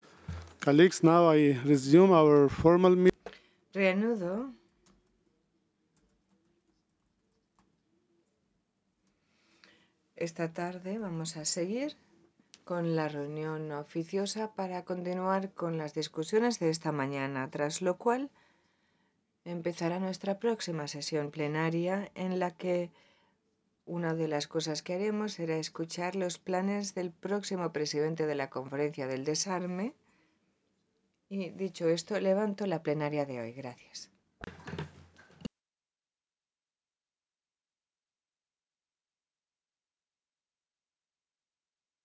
PRESIDENT 12:58:48 0:00:10 00:00:33
Синхронный перевод заседаний, включая субтитры, обеспечивается Организацией Объединенных Наций для облегчения общения, поскольку существует шесть официальных языков Организации Объединенных Наций.